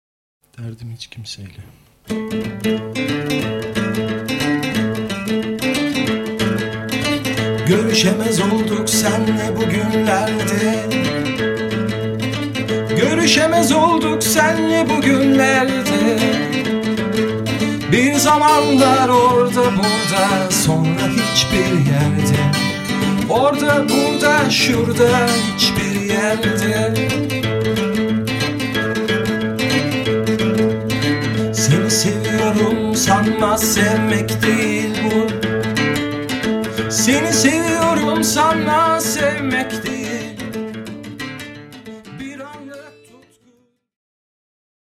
Vokal, akustik ve elektrik gitarlar, klavye, armonika
Davul düzenlemeleri
Geri vokal